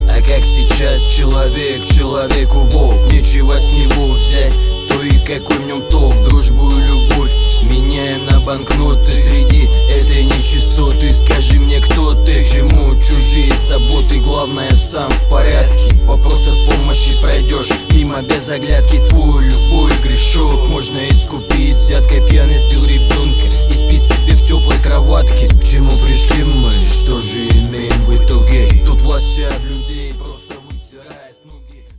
• Качество: 320, Stereo
русский рэп